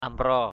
/a-mrɔ:/ (d.) sò = moule. mussel.